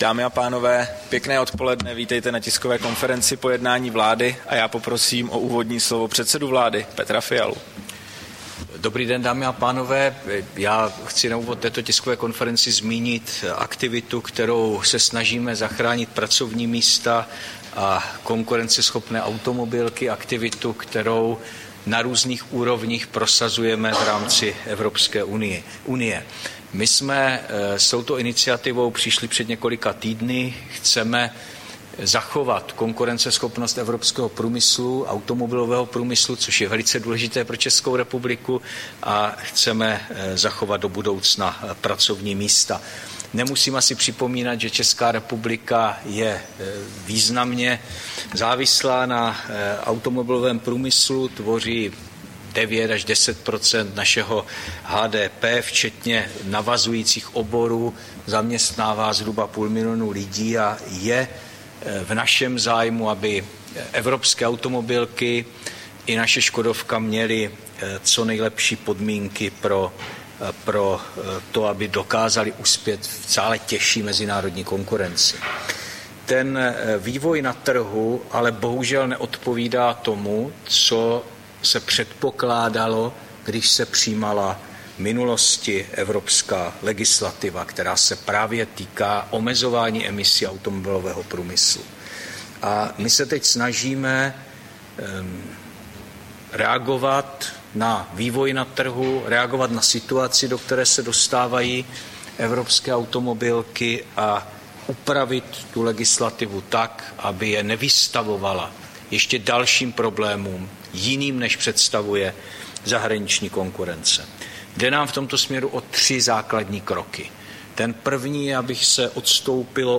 Tisková konference po jednání vlády, 4. prosince 2024